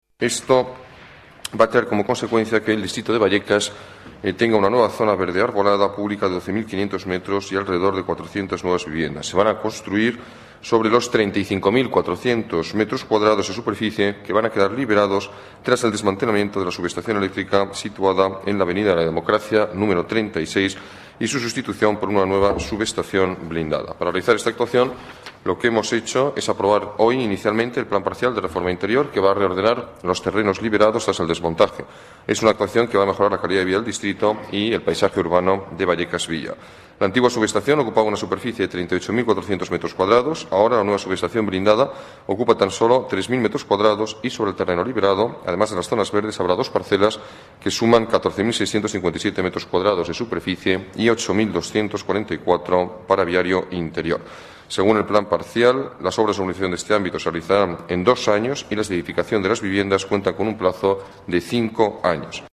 Nueva ventana:Alberto Ruiz Gallardon explica el proyecto de desmontaje de la subestación eléctrica, que generará nuevas zonas verdes y 400 viviendas en Vallecas Villa